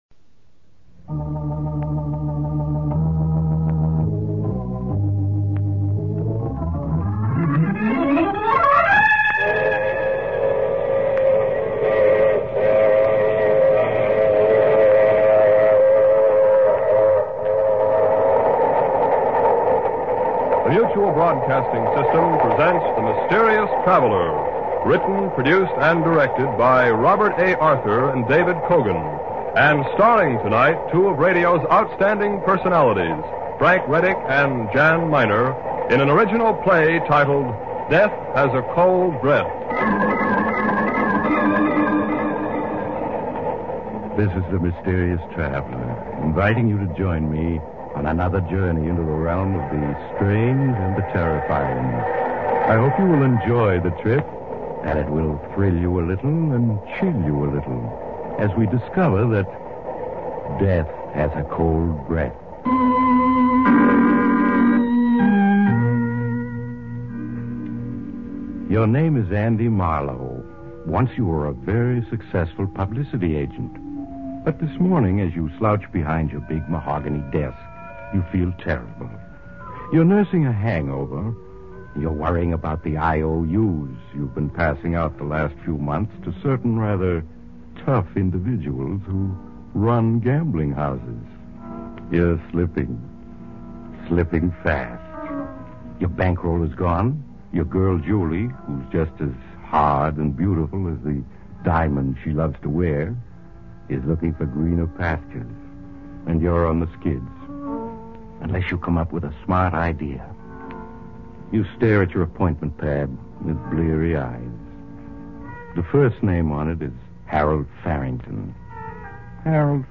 old time radio